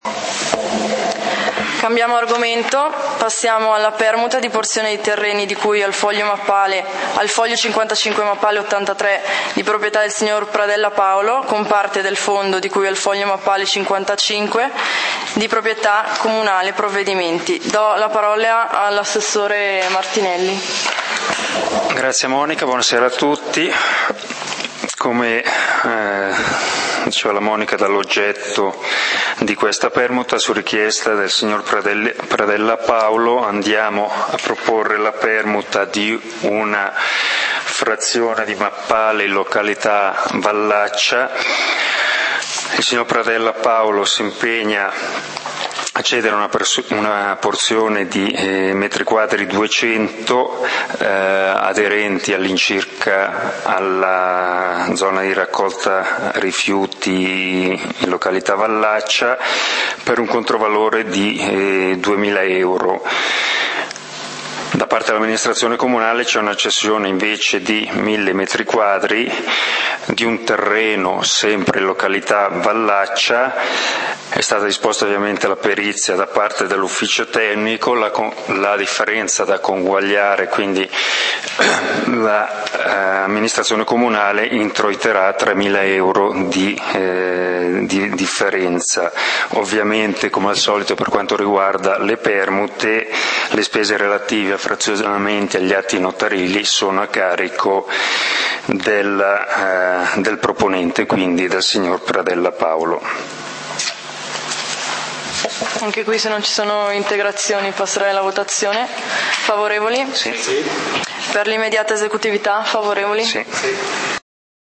Consiglio comunale di Valdidentro del 14 Ottobre 2013